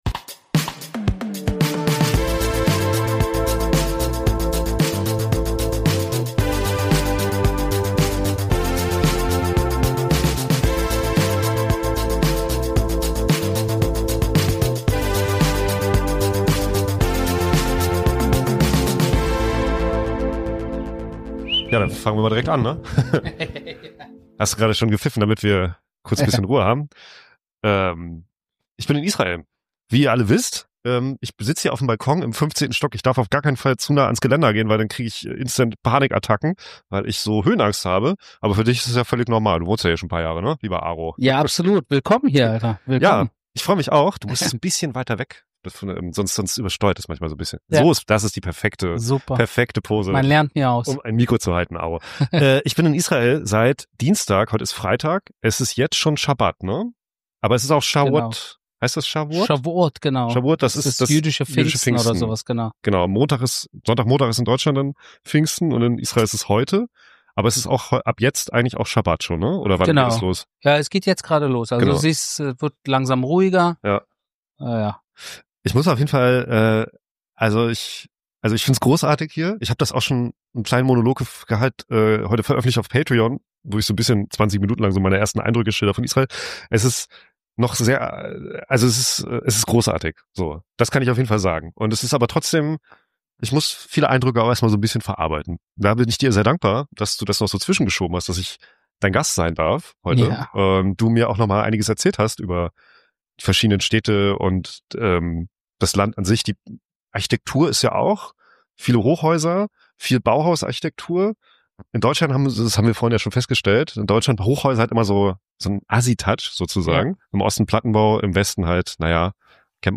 Ich bin bei Familie Shalicar zuhause im 15. Stock und bevor wir den Shabbat mit einem phantastischen Essen beginnen, steht mir Arye Sharuz alias Boss Aro nochmal Rede und Antwort: zu seinem neuen Buch, zur israelischen Gesellschaft, zum Wohnen in...